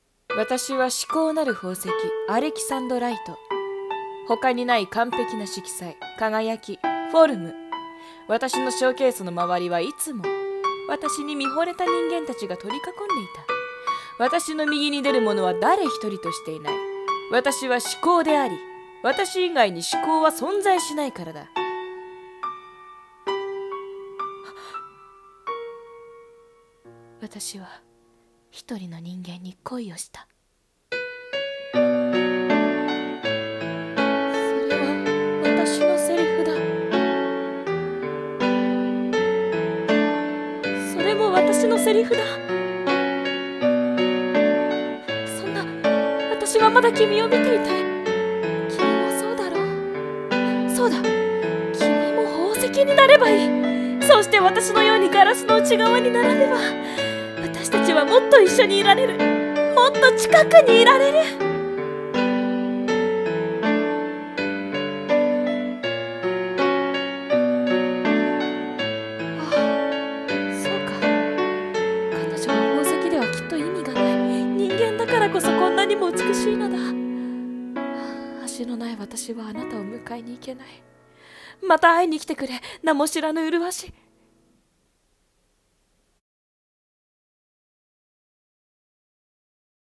【声劇】至高なるアレキサンドライトの恋